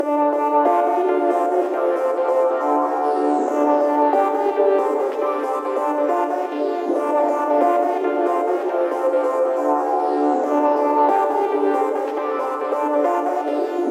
Tag: 138 bpm Electronic Loops Synth Loops 2.34 MB wav Key : D FL Studio